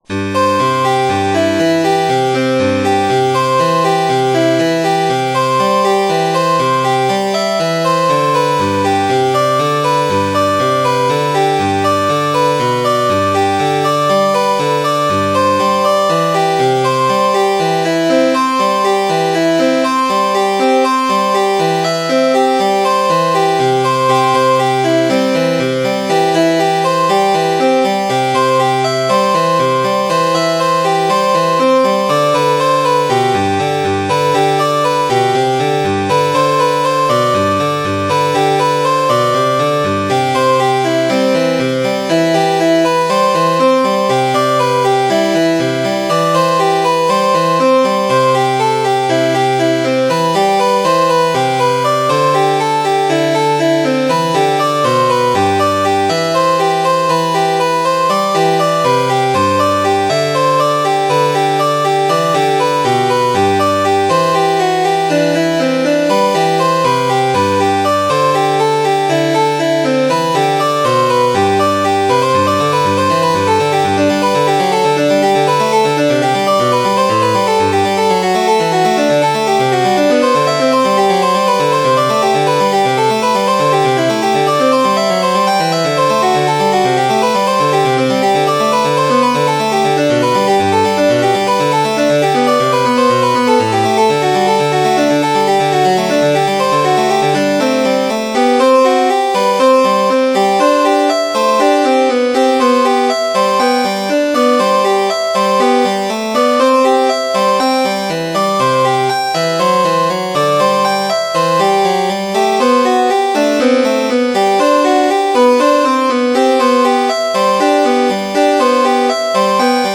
Meditation No.2 in G for solo harpsichord. Instrumental inspired by a visit to the musical museum Finchcocks.
Meditation No.2 in G for solo harpsichord.wma